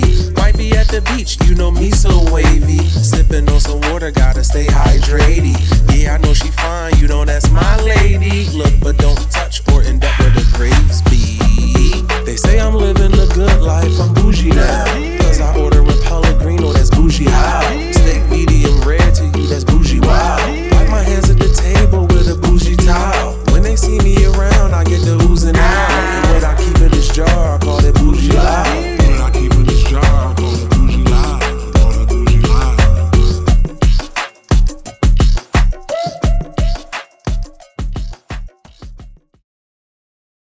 Hip-Hop - Boujie_SDE2yACz1r.wav